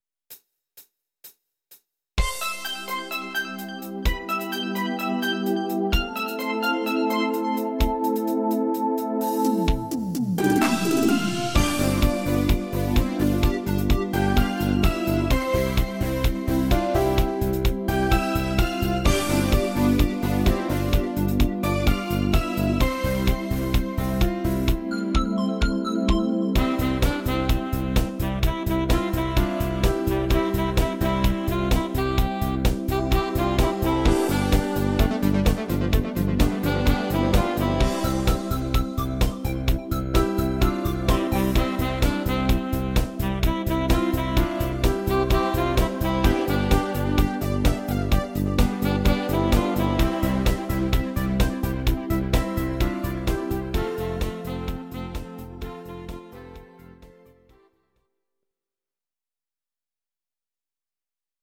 Audio Recordings based on Midi-files
Pop, German, 2010s